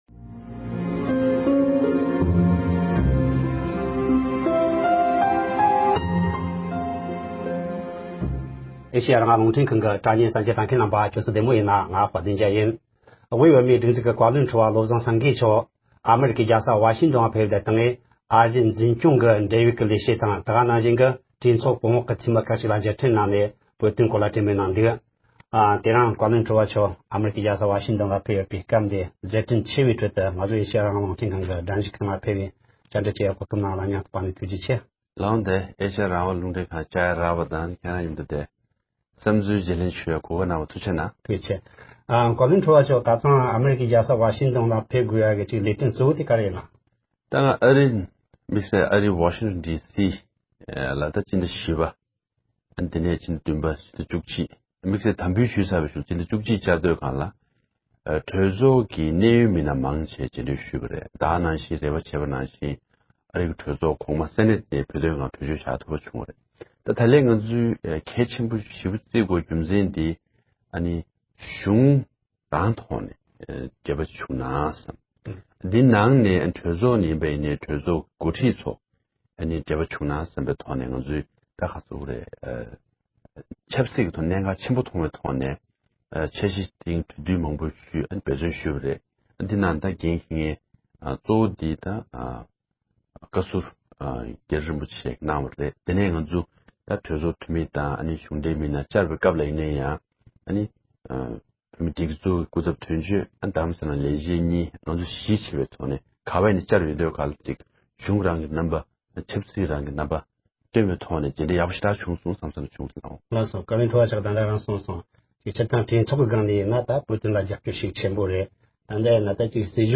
བཀའ་བློན་ཁྲི་པ་མཆོག་ལ་བཅར་འདྲི།